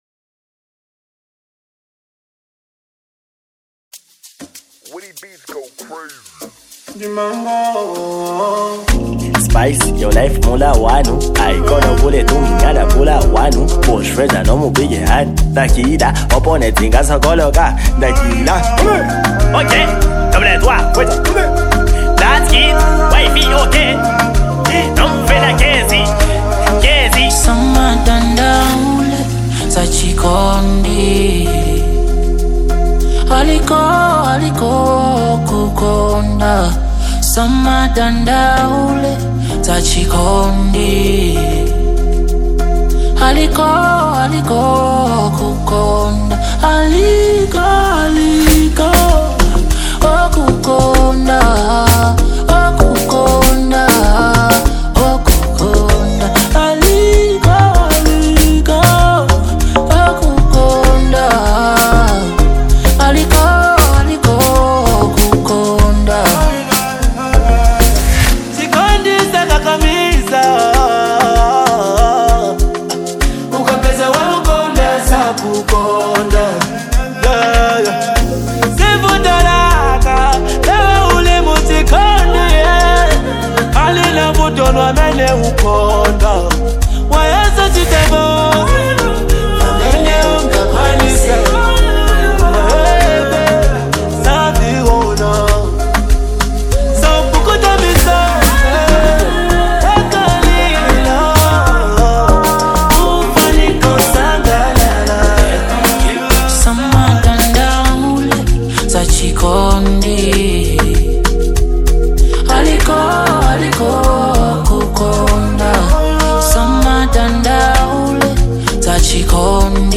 is a vibrant track